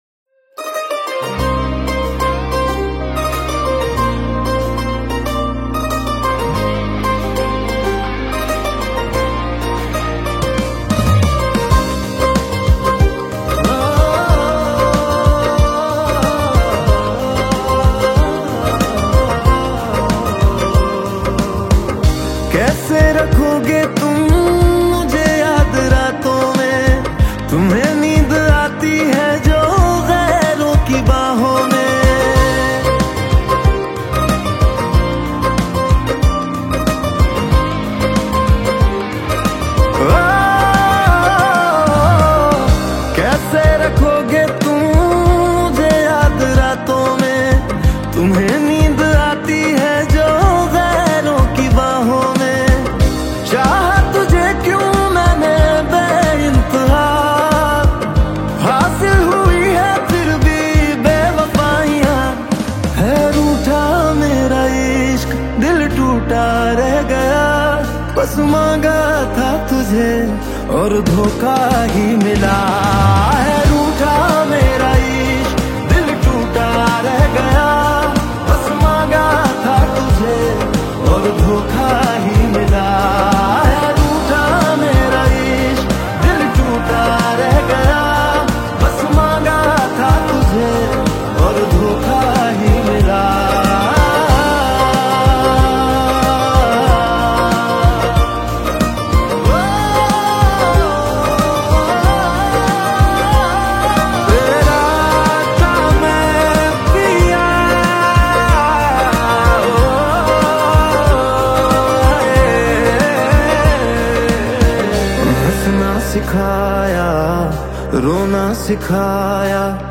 emotional Hindi song